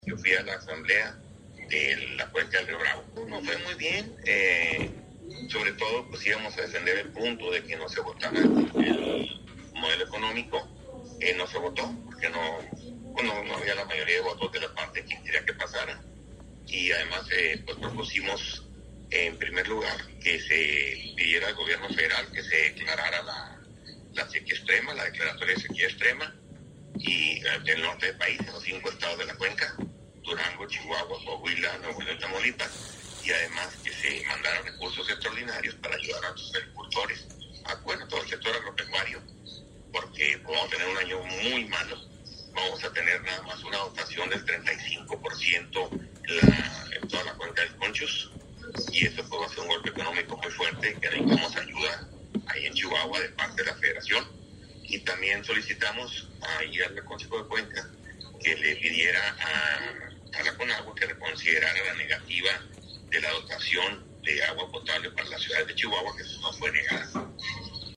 AUDIO: MARIO MATA CARRASCO, DIRECTOR EJECUTIVO DE LA JUNTA CENTRAL DE AGUA Y SANEAMIENTO (JCAS)